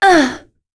Lorraine-Vox_Damage_01.wav